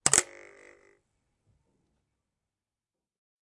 刀片振动和故障 " Knive1
描述：记录的刀片声音。
Tag: 刀片声 单击 打击乐器 录音 毛刺 叶片 振动 现场录音 拍摄 声音